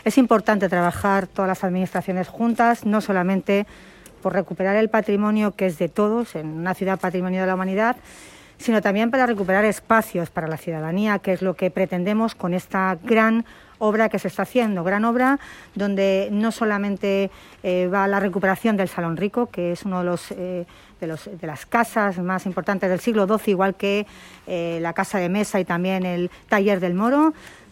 AUDIOS. Milagros Tolón, alcaldesa de Toledo